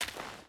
Dirt Walk 2.wav